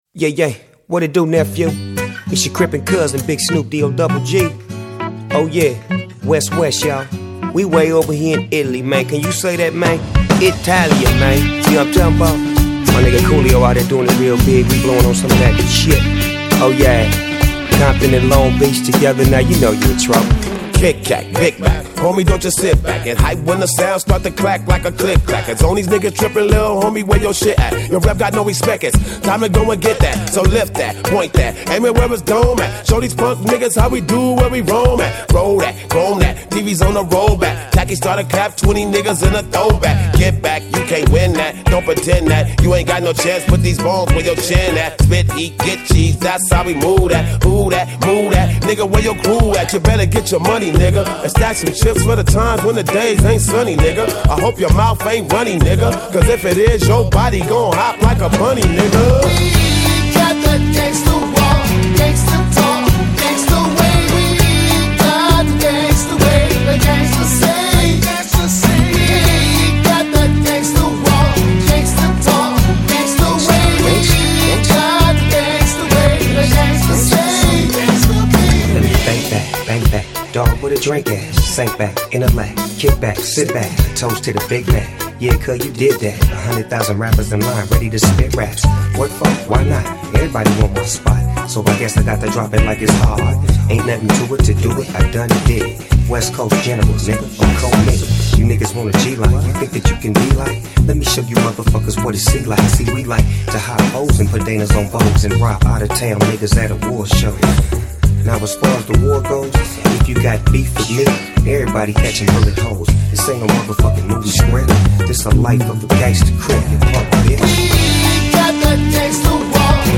Жанр: foreignrap
Рэп Хип-хоп.